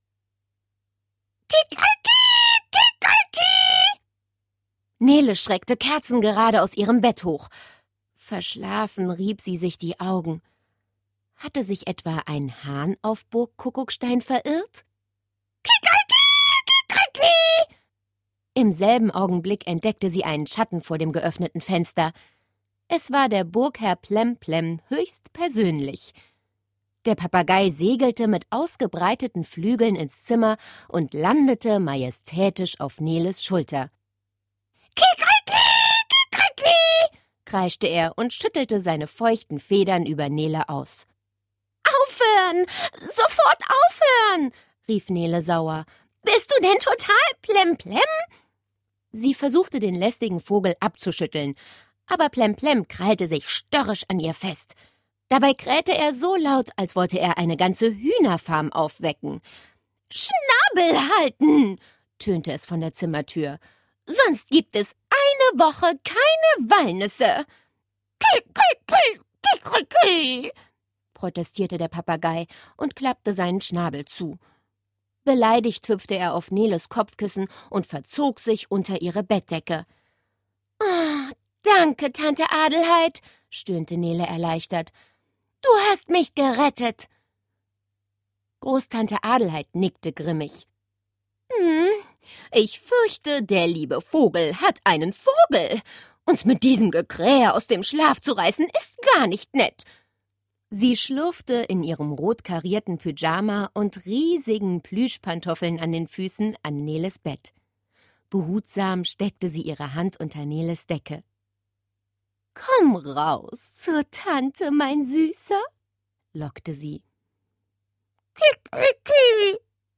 tiptoi® Hörbücher ► Ravensburger Online-Shop
Nele_im_Zeltlager-Hoerprobe.mp3